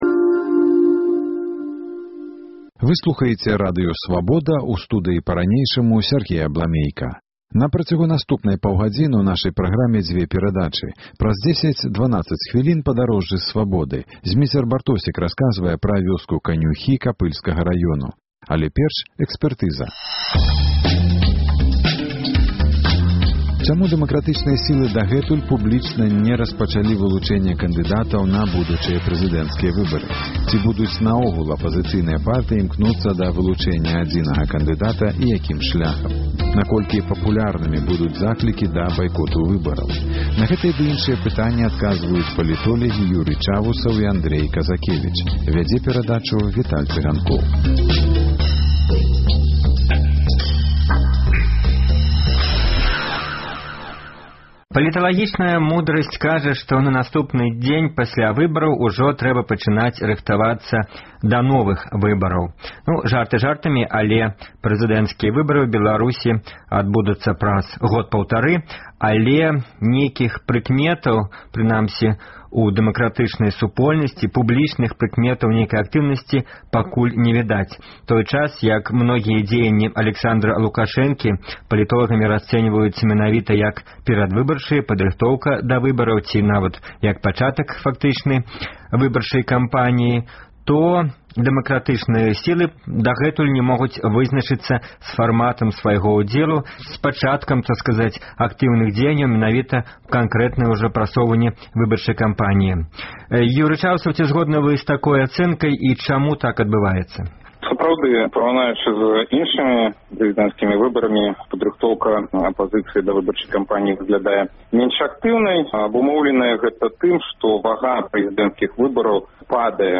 На гэтыя ды іншыя пытаньні адказваюць палітолягі